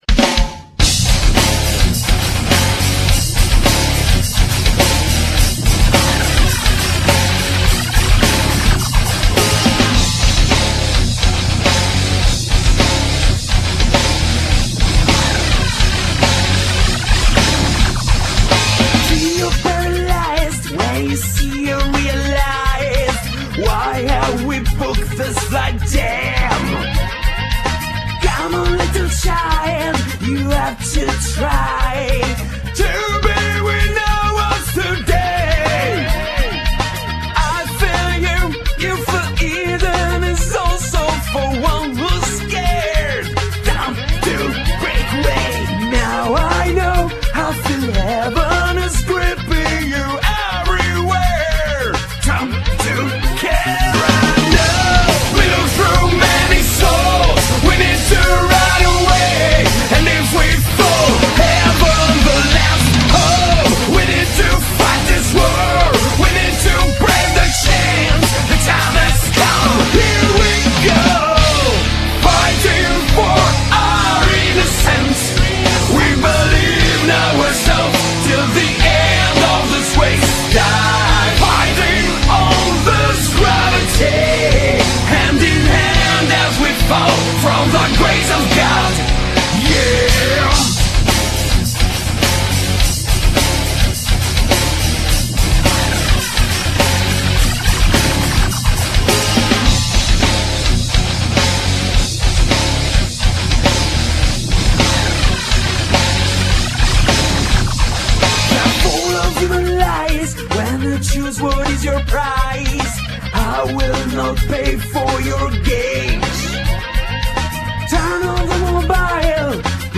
Genere : Pop
Buono il mix fra linee vocali e strumentali.